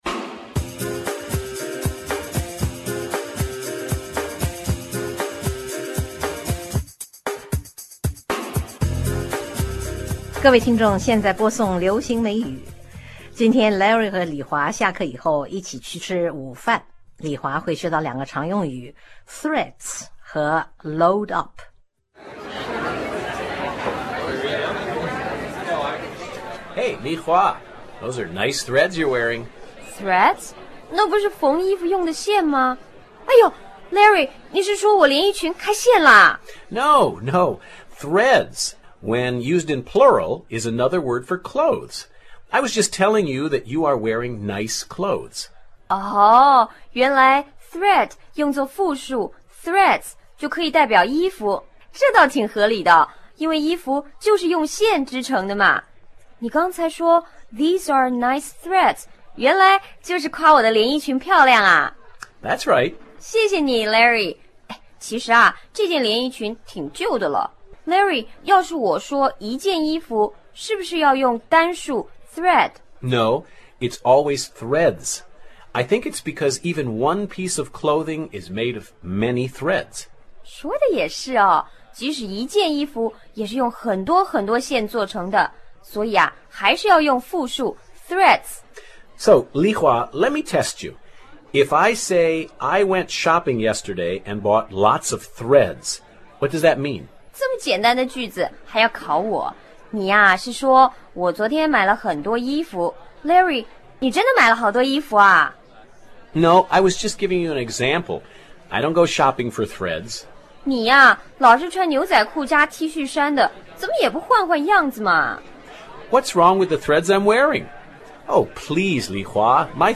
(campus noise)